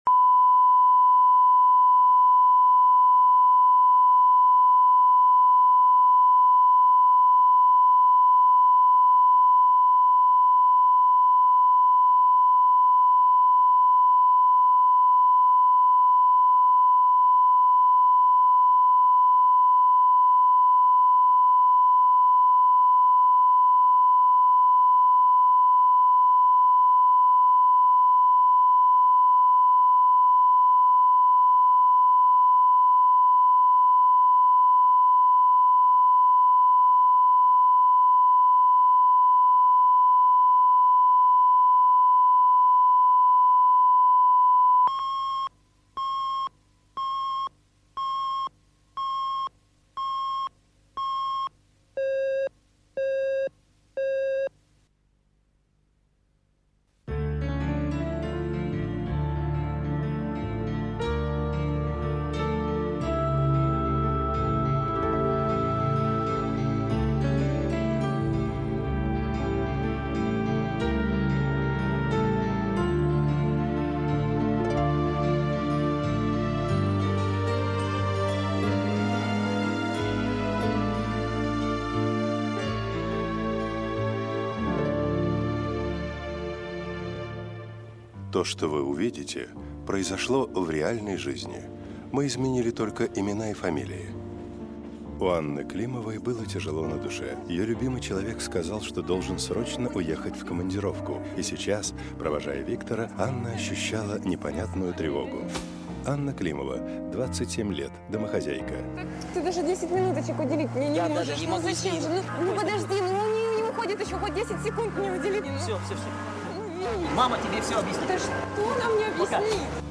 Аудиокнига За призрачной стеной | Библиотека аудиокниг
Прослушать и бесплатно скачать фрагмент аудиокниги